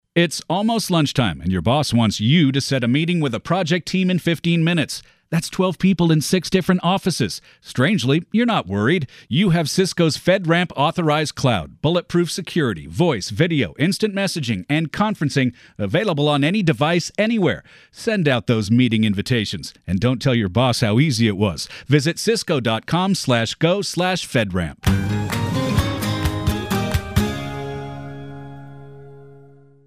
CISCO SYSTEMS radio ad with sting (1).mp3